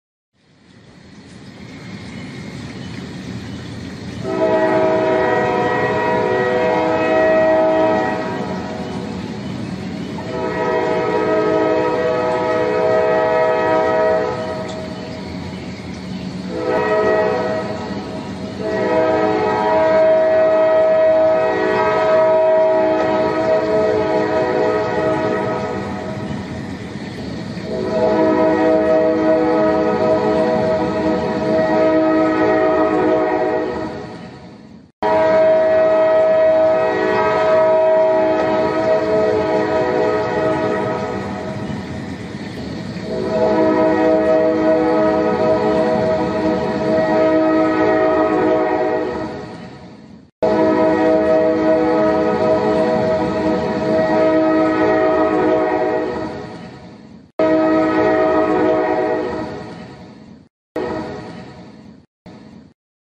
دانلود صدای سوت قطار 1 از ساعد نیوز با لینک مستقیم و کیفیت بالا
جلوه های صوتی